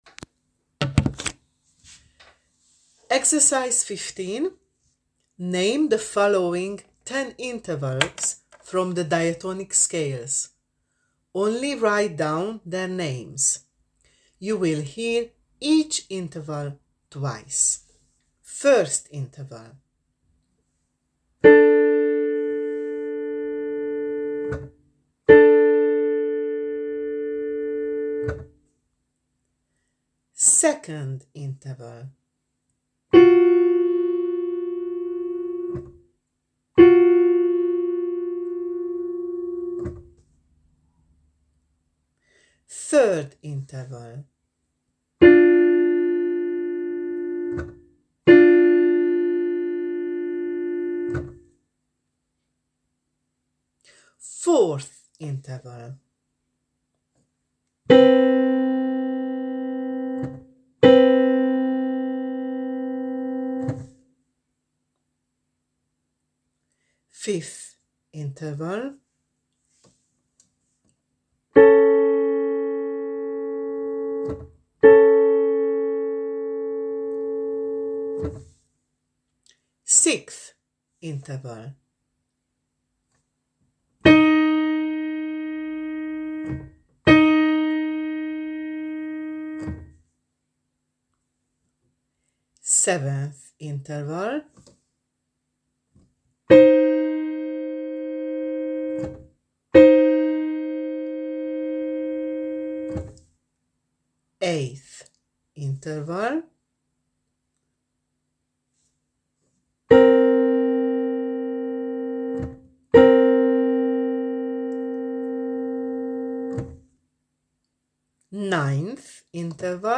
15.Name the following 10 intervals (from the diatonic scales). Only write down their names: Example:m2 m7  M7  etc. You will hear each interval twice: